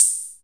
CR78 open hat.WAV